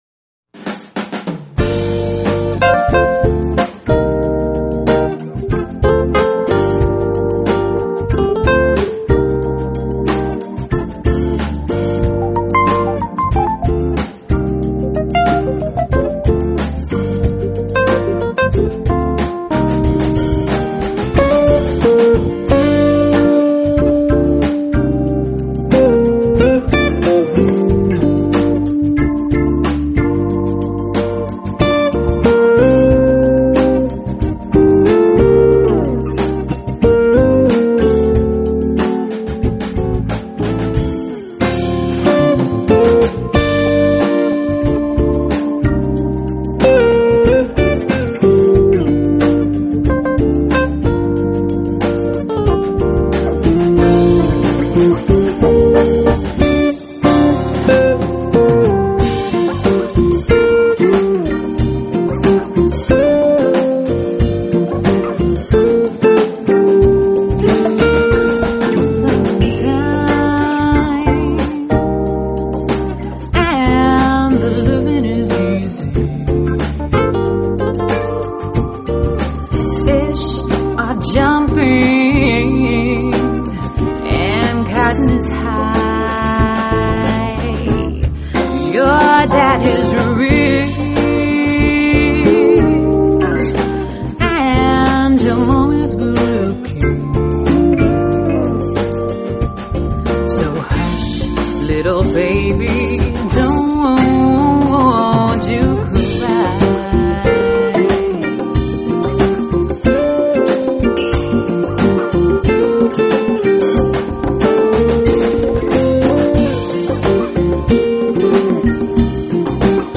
Smooth Jazz Fusion
soulful vocals